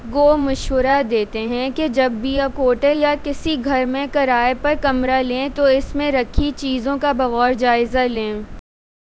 Spoofed_TTS/Speaker_10/117.wav · CSALT/deepfake_detection_dataset_urdu at main